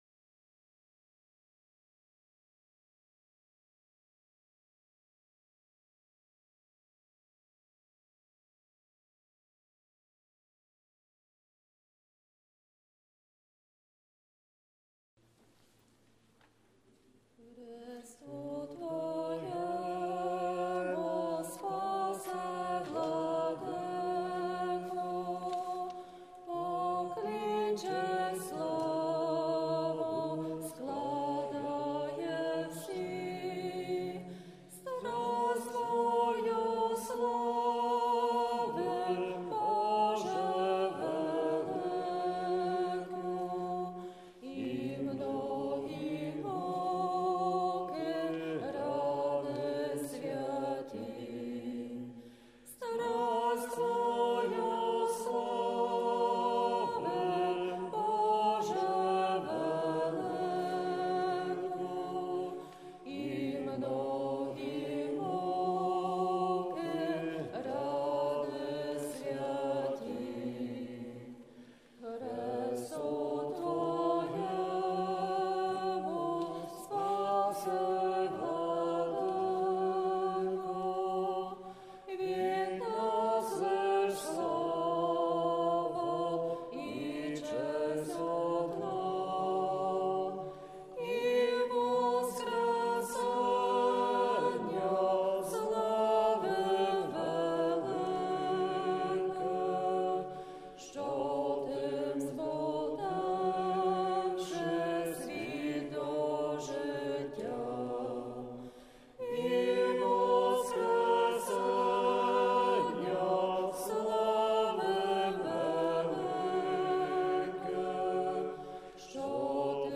Неділя 22 Березня 2020 Божественна Літургія Василя Великого